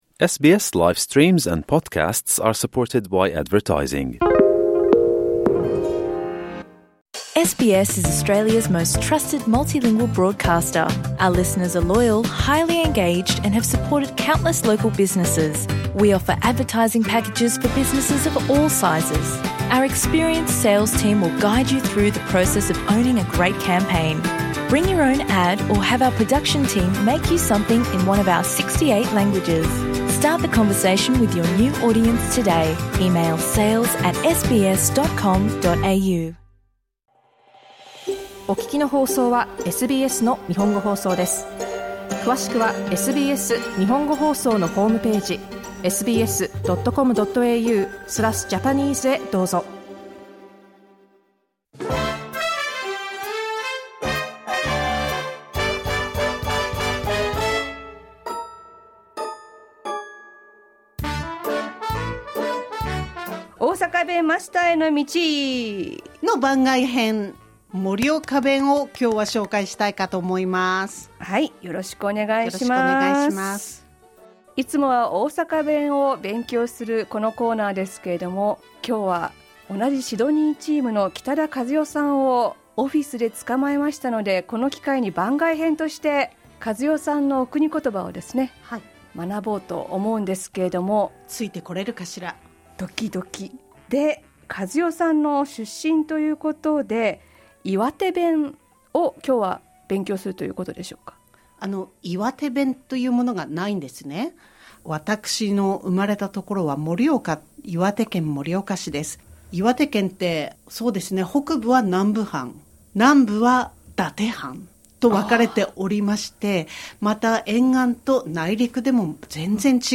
The Japanese language is truly rich in diversity. Let's learn the Morioka dialect with SBS Japanese Sydney team.